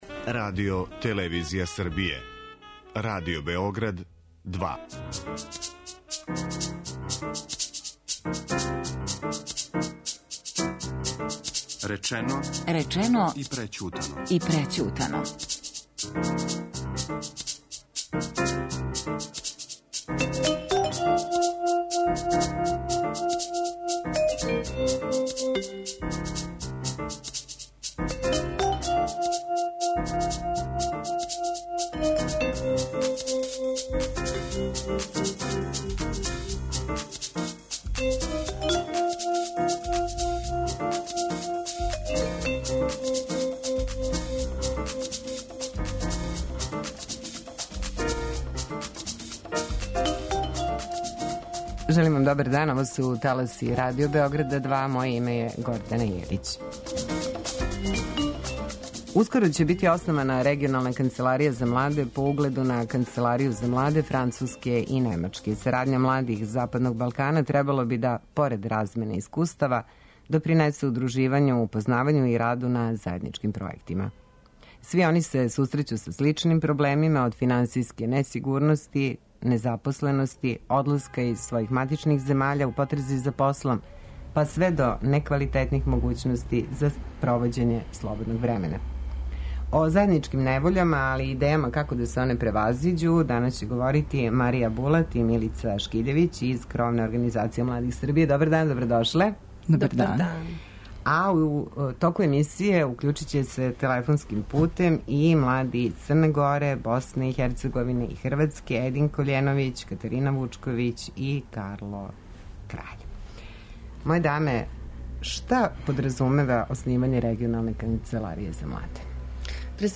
а у разговор ће се телефонским путем укључити и млади из Црне Горе, БиХ и Хрватске